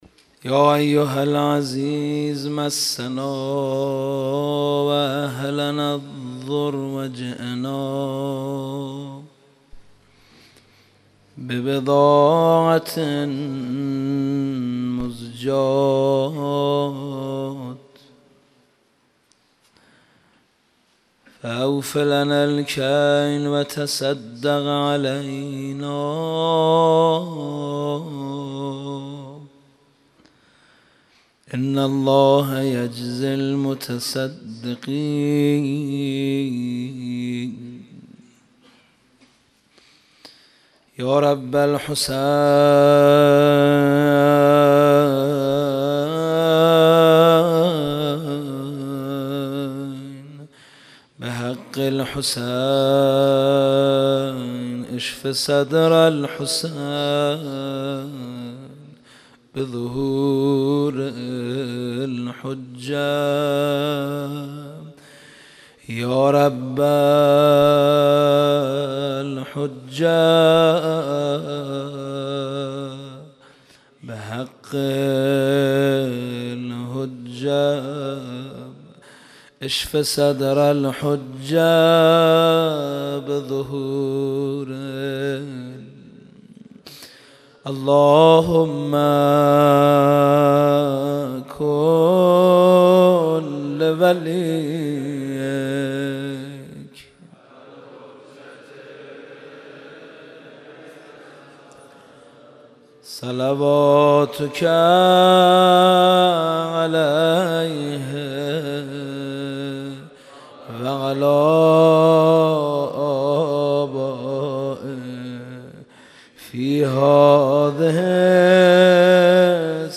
شب سوم رمضان 95، حاح محمدرضا طاهری